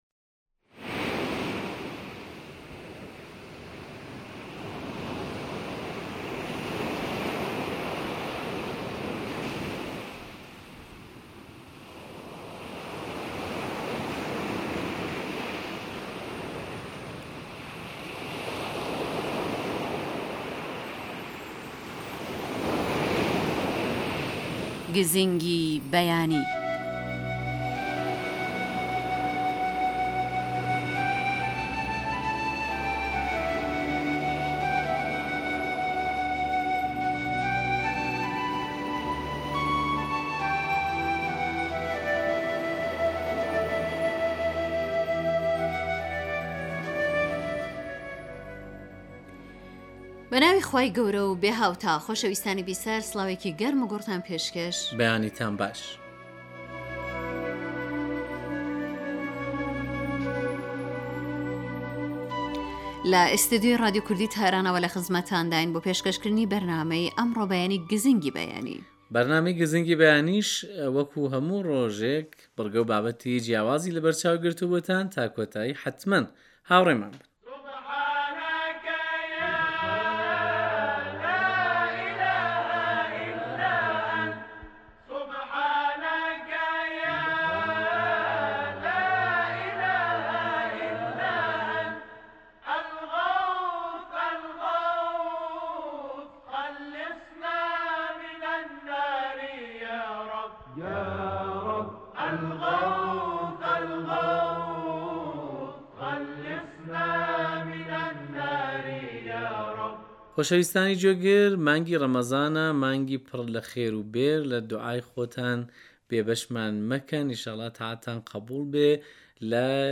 گزینگی بەیانی بەرنامەیێكی تایبەتی بەیانانە كە هەموو ڕۆژێك لە ڕادیۆ كەردی تاران بڵاو دەبێتەوە و بریتییە لە ڕاپۆرت و دەنگی گوێگران و تاووتوێ كردنی بابەتێكی پزیشكی.